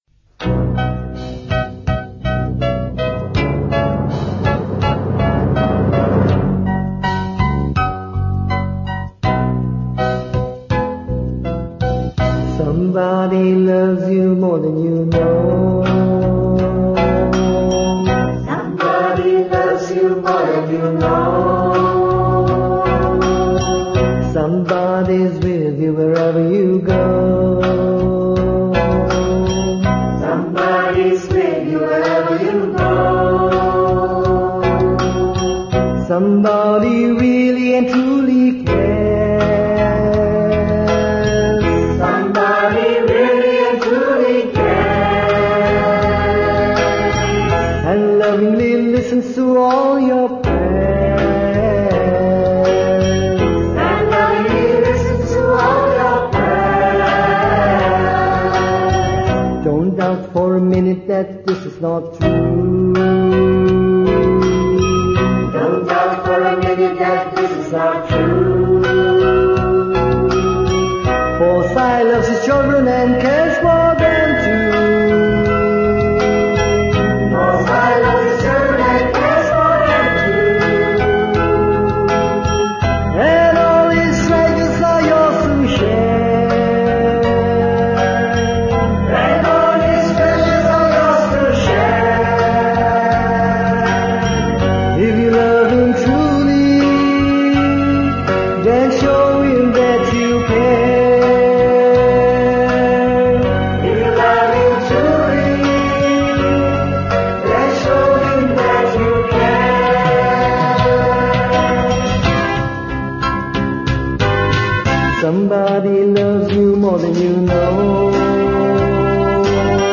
1. Devotional Songs
~ Major (Shankarabharanam / Bilawal)
8 Beat / Keherwa / Adi
Medium Slow